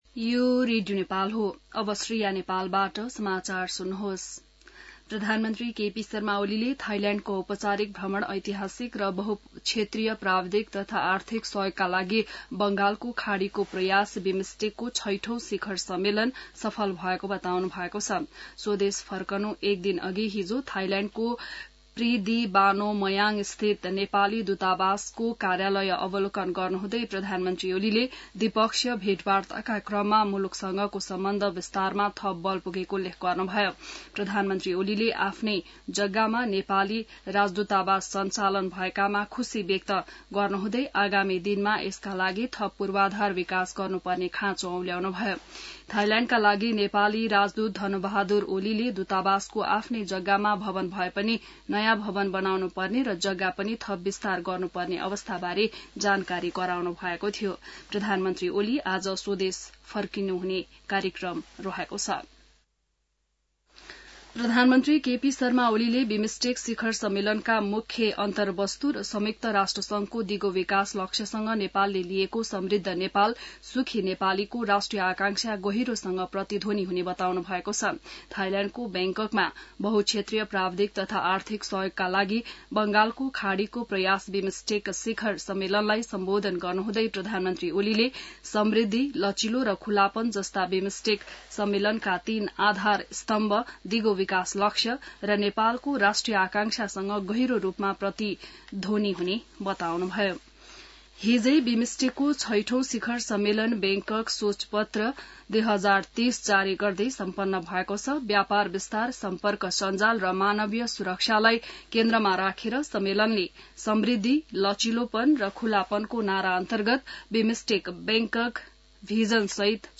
An online outlet of Nepal's national radio broadcaster
बिहान ६ बजेको नेपाली समाचार : २३ चैत , २०८१